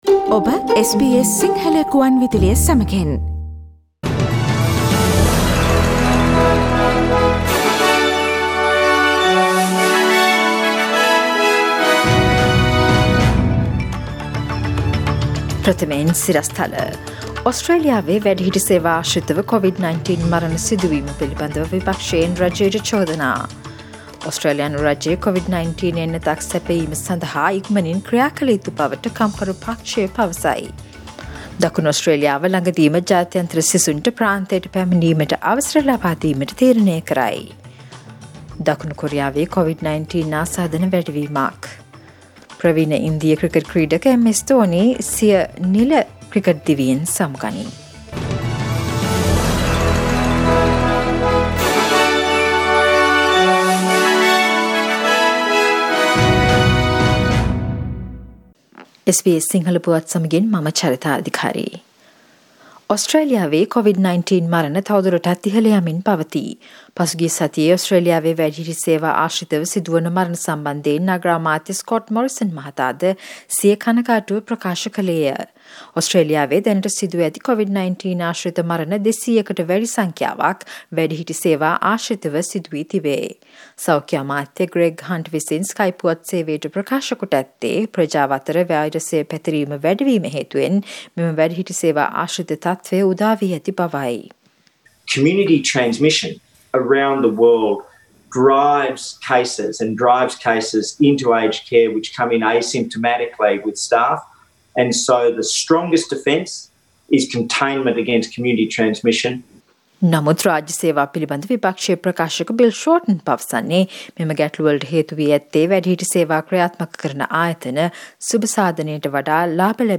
Daily News bulletin of SBS Sinhala Service: Monday 17 August 2020
Today’s news bulletin of SBS Sinhala Radio – Monday 17 August 2020 Listen to SBS Sinhala Radio on Monday, Tuesday, Thursday and Friday between 11 am to 12 noon